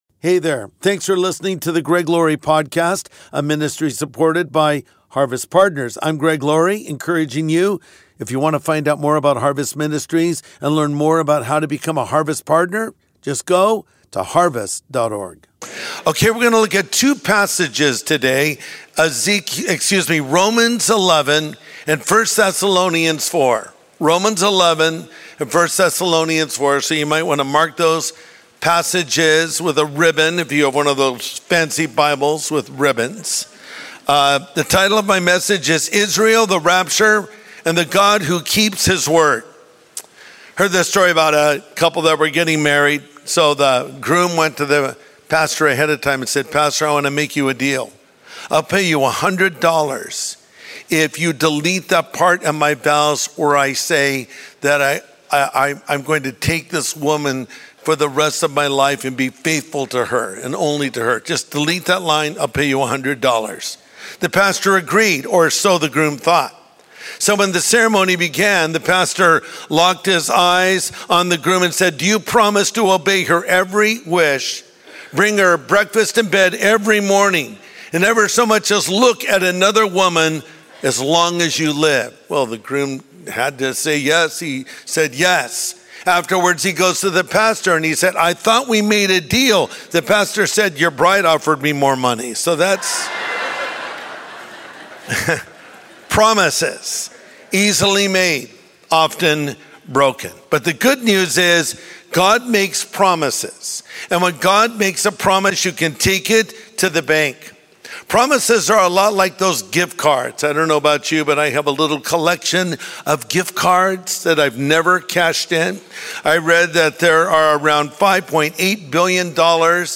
Israel, the Rapture, and the God Who Keeps His Word | Sunday Message Podcast with Greg Laurie
Pastor Greg Laurie provides his biblical perspective on Israel and the end times in today's message.